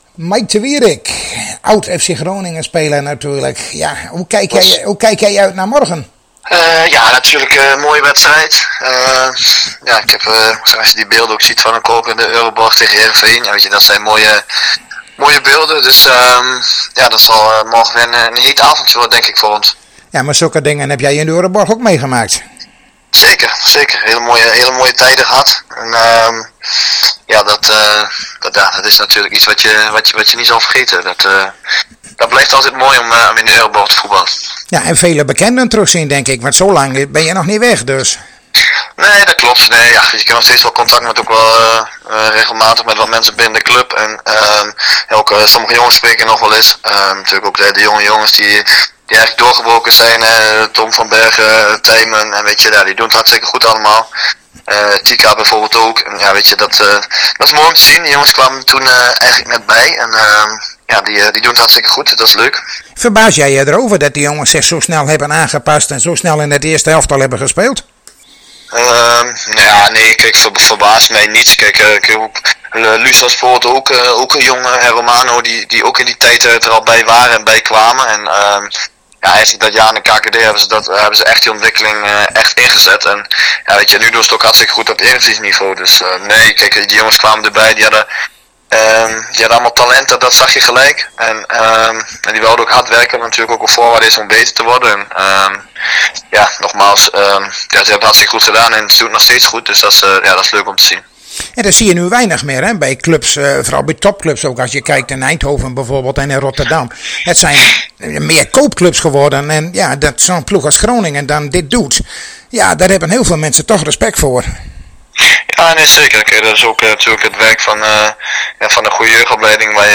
Zojuist spraken wij met Mike te Wierik over zijn tijd bij FC Groningen en over de wedstrijd van morgen als zijn huidige club Heracles Almelo naar de Euroborg komt voor de wedstrjd tegen FC Groningen.